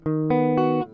吉他琶音3
描述：这是一些有用的吉他琶音，是我在空闲时间录制的。
Tag: 琶音 和弦 吉他 爵士乐 醇厚 旋律